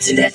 VEE Synth Voc 15.wav